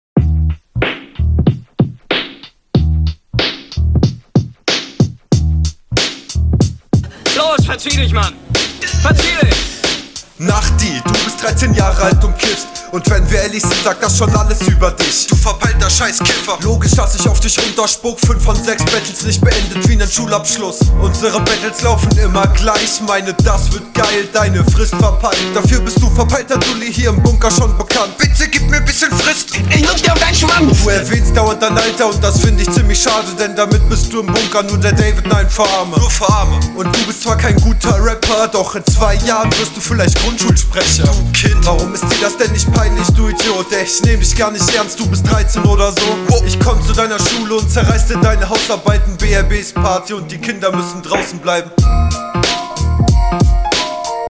Cooler Beat, auf dem kommst du gleich viel fresher wie in deiner rr.